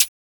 Closed Hats
HiHat (Aim For The Moon).wav